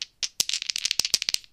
dieShuffle3.ogg